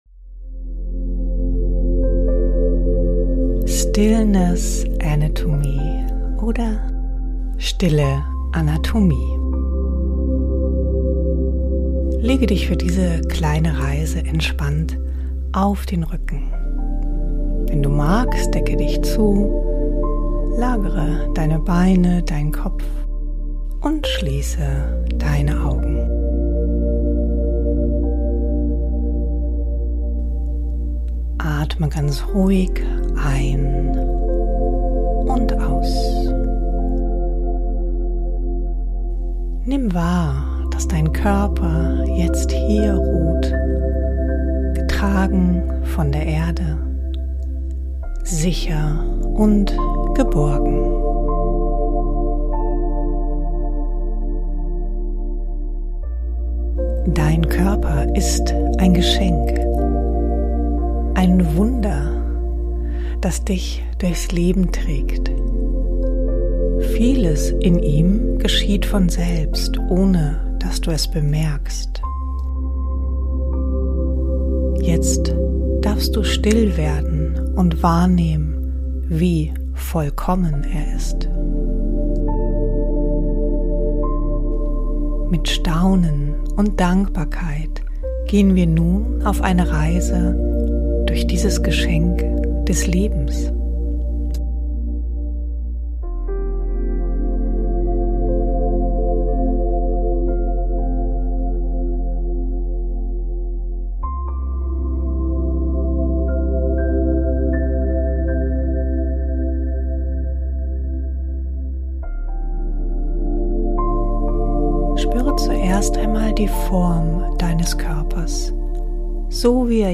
In dieser Meditation darfst du ihn bewusst wahrnehmen: spüren, wie er dich trägt, schützt und verbindet – bis hin zu deinem Atem, der dich nährt und dich in die Fülle führt. Nimm dir einen Moment nur für dich, lege dich entspannt hin und lass dich von dieser stillen Körperreise begleiten.